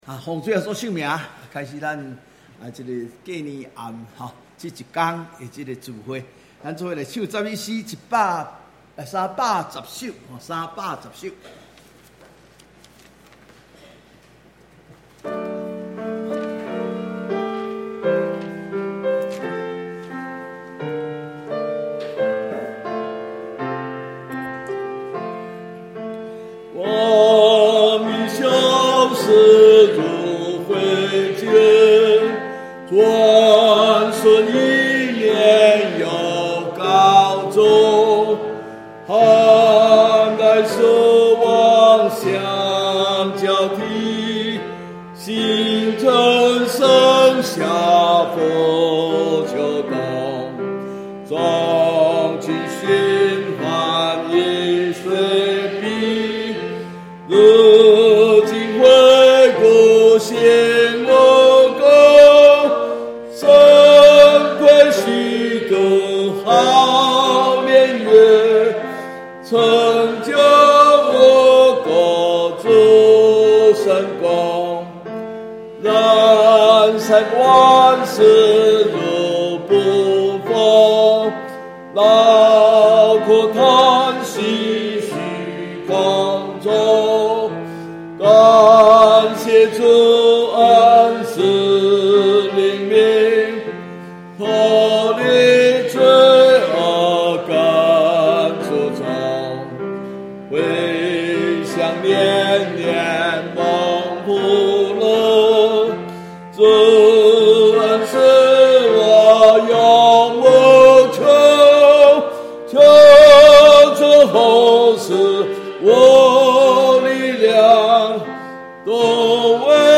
除夕特別聚會